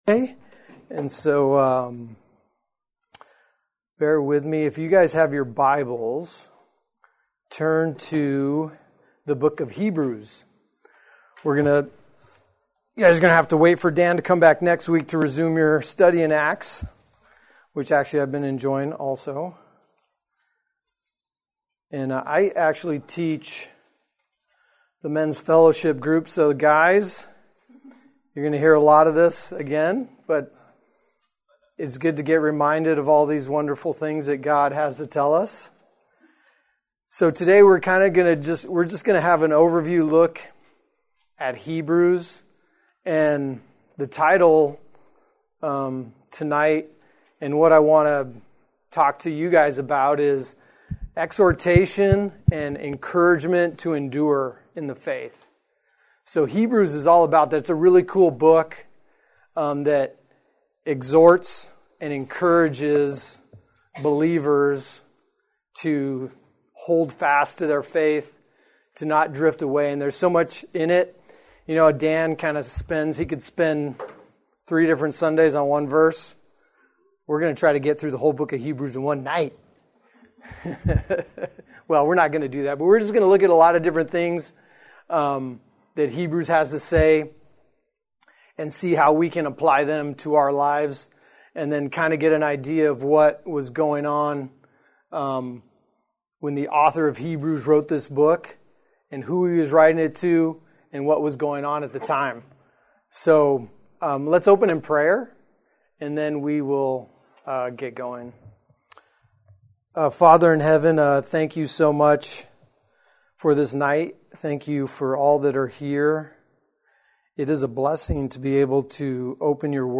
The newest sermons from Sovereign Grace Bible Church on SermonAudio.